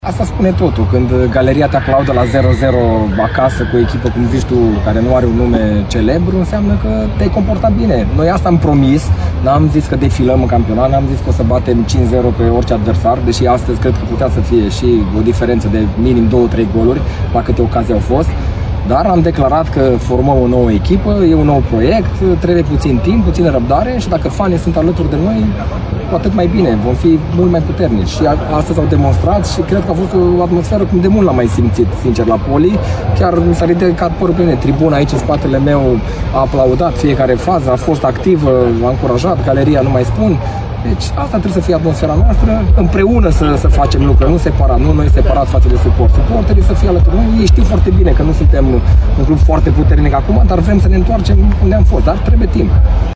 Pe stadion au fost aproximativ 800 de spectatori, iar suporterii și-au aplaudat favoriții la final, pentru efortul depus pe o căldură sufocantă. Paul Codrea spune că, în unele momente, i „s-a ridicat părul pe mână” datorită atmosferei: